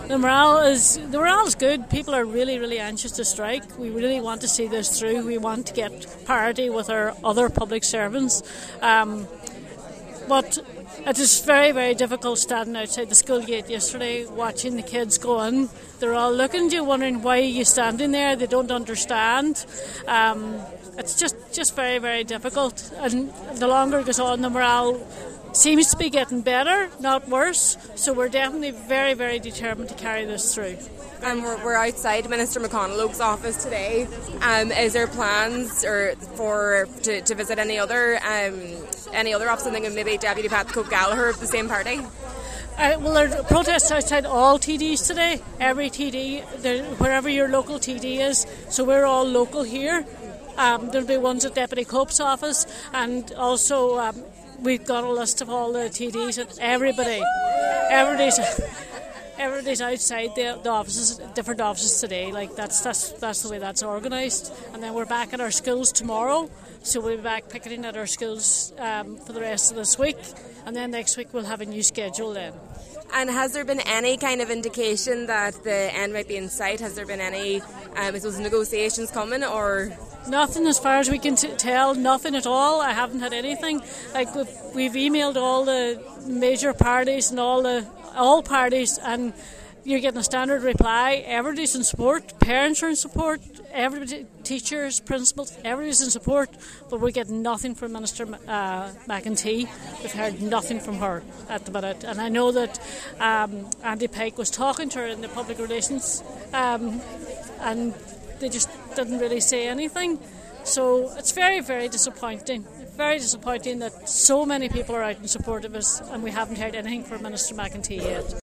Highland Radio News spoke to those standing out in protest, and three recurring themes came into conversation: guilt, fear, and anger.